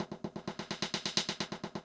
drums05.wav